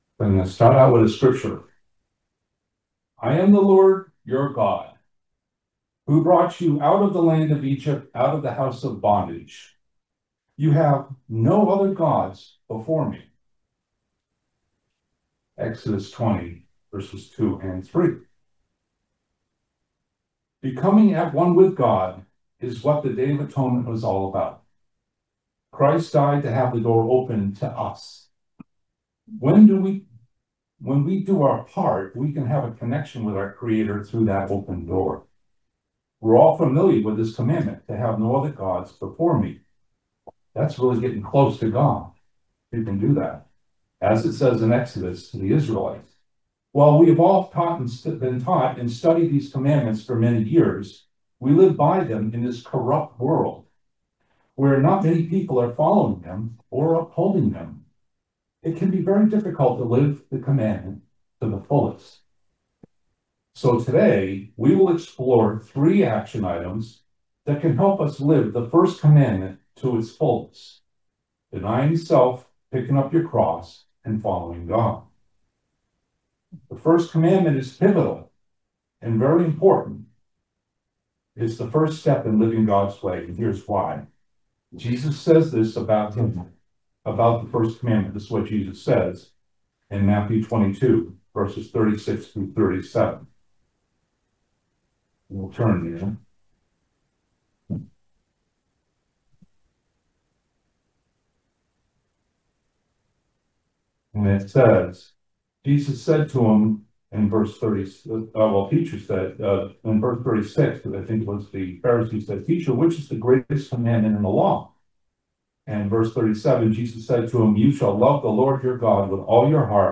In this video sermon , we'll explore 3 action items to help us to better understand and keep the First Commandment.